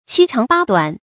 七長八短 注音： ㄑㄧ ㄔㄤˊ ㄅㄚ ㄉㄨㄢˇ 讀音讀法： 意思解釋： 形容高矮、長短不齊。